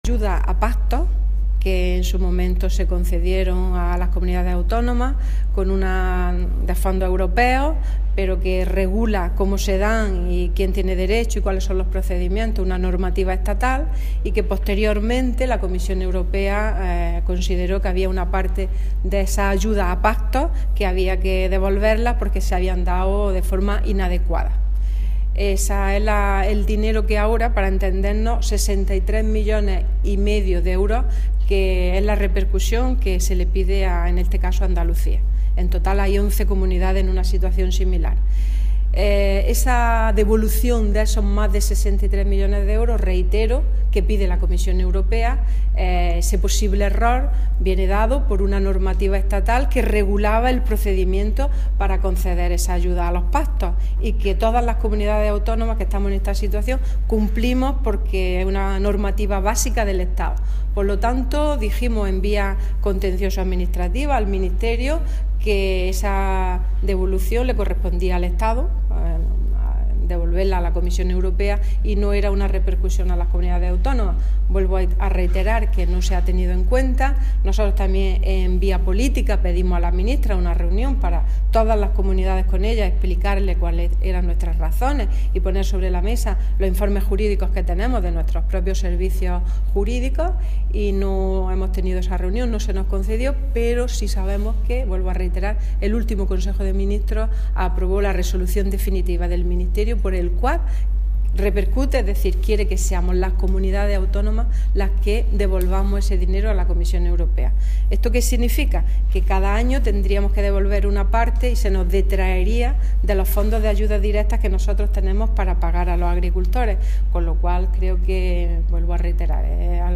Carmen Ortiz, en la Mesa de Interlocución Agraria.
Declaraciones consejera Mesa Interlocución